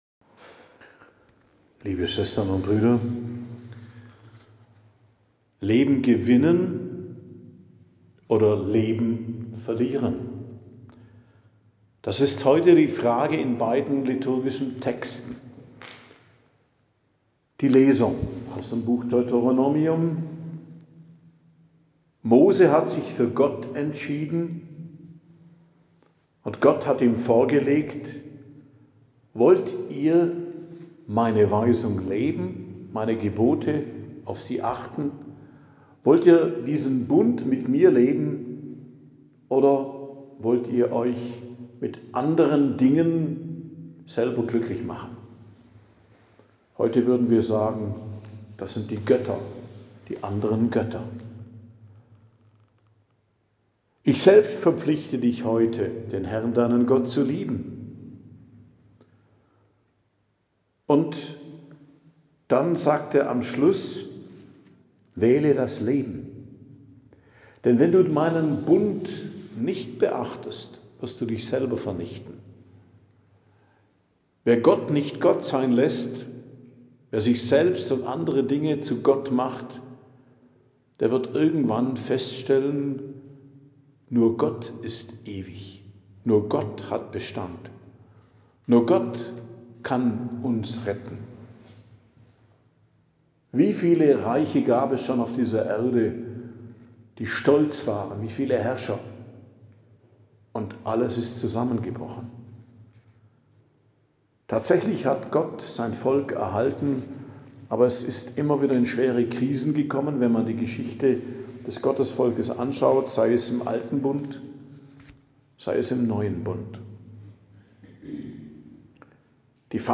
Predigt am Donnerstag nach Aschermittwoch, 19.03.2026